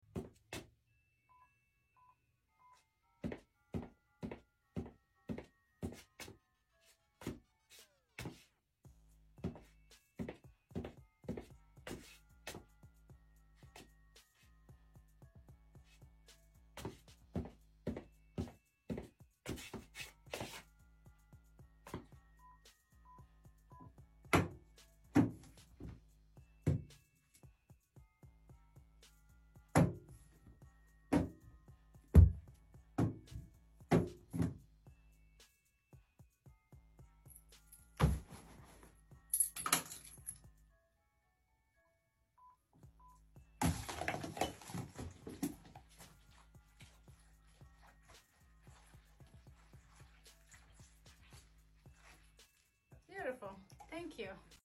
920 Gorilla Foley what sound effects free download